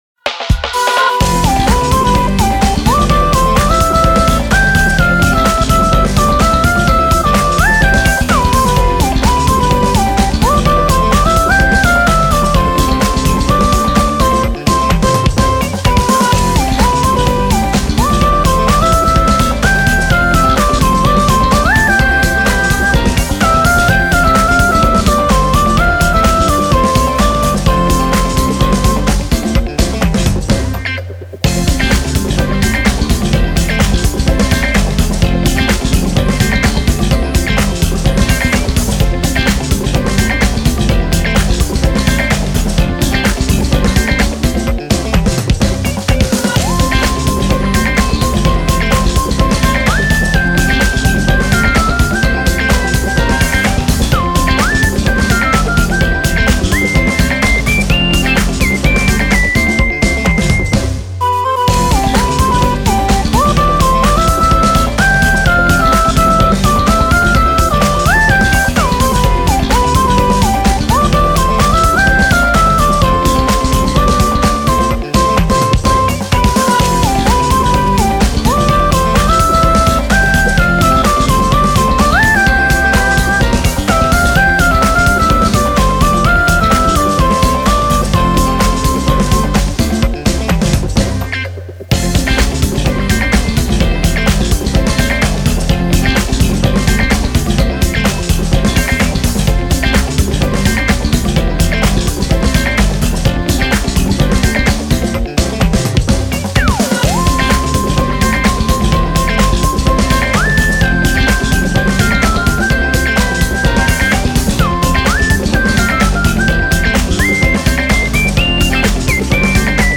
微妙にミックス処理が違ったり、一部メロディを追加したりしております。